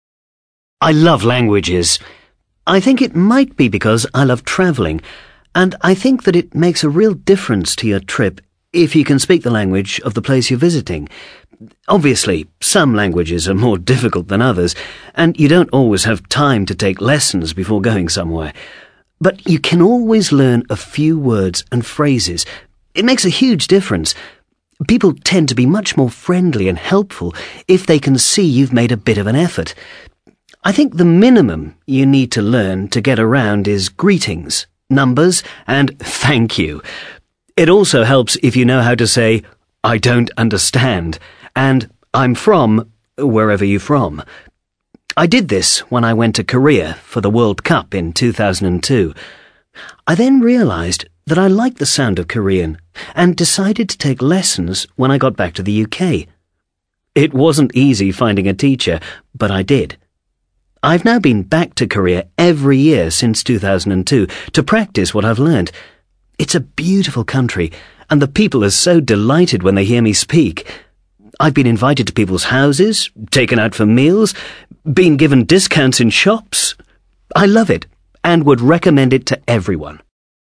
MAN